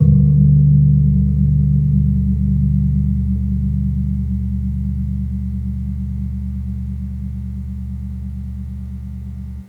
Gong-C1-p.wav